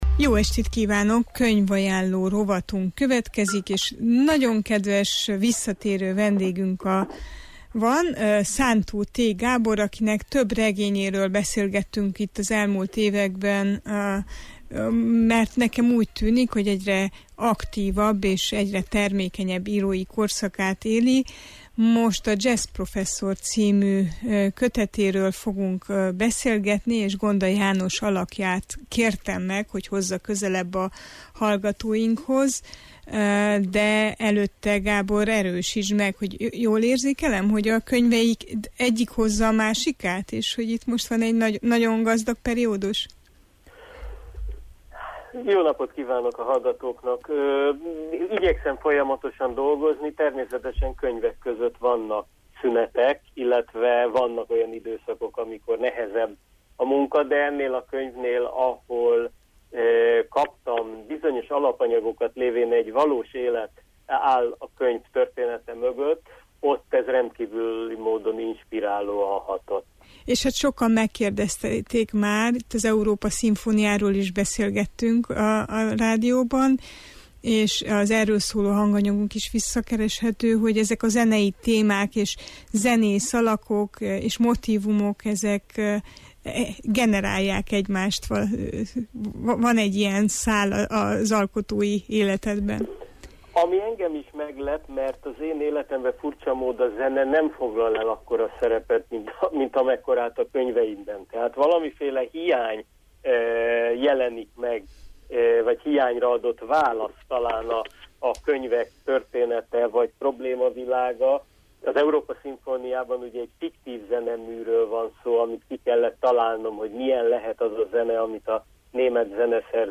Szántó T. Gábor A jazzprofesszor című regénye a feldolgozhatatlan emlékek és a felszabadító élmények és érzések világába vezet, az Értsünk Szót adásában kérdeztük a szerzőt